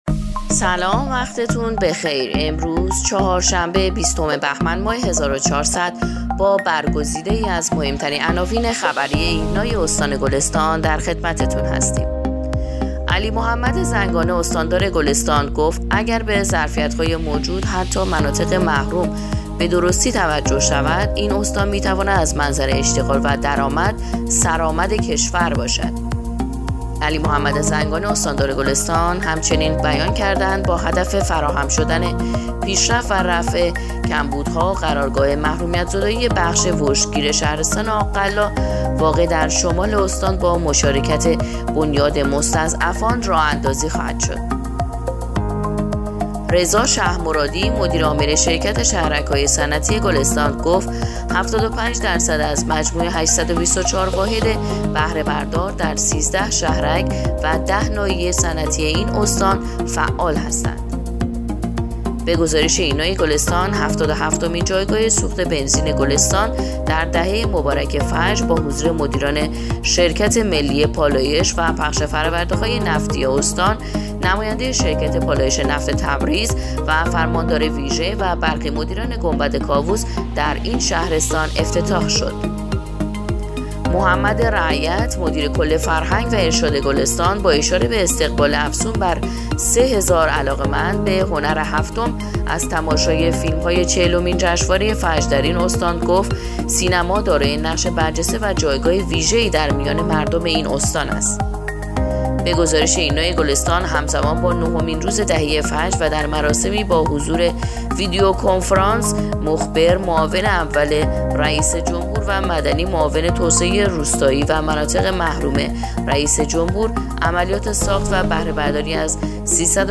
پادکست/ اخبار شبانگاهی بیستم بهمن ماه ایرنا گلستان